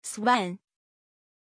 Aussprache von Sven
pronunciation-sven-zh.mp3